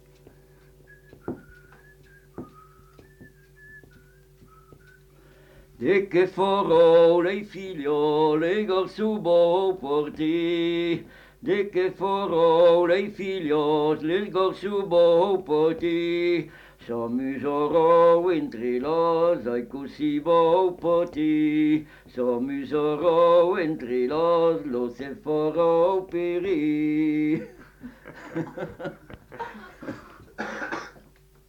Aire culturelle : Viadène
Lieu : Vernholles (lieu-dit)
Genre : chant
Effectif : 1
Type de voix : voix d'homme
Production du son : chanté
Danse : bourrée